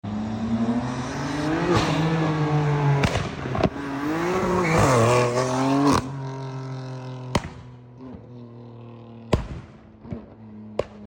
M3cs Shooting Flames 🔥 Sound Effects Free Download